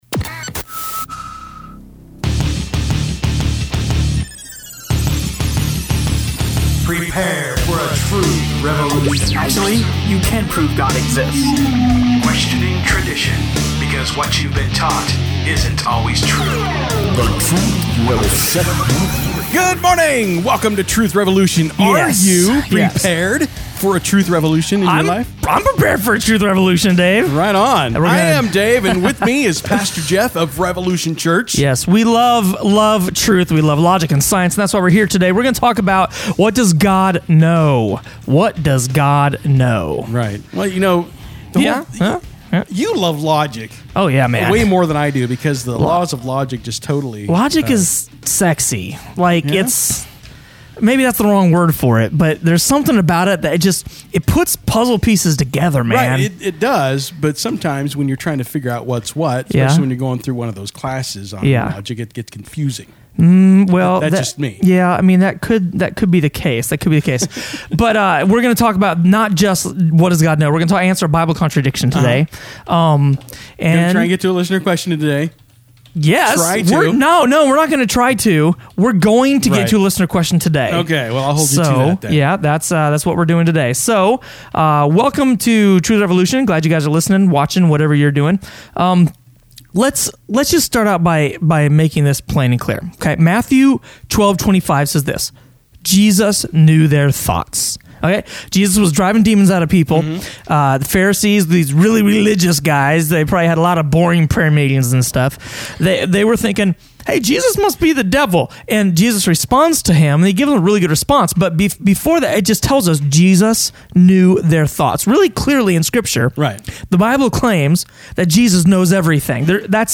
What Does God Know? | Truth Revolution Radio Show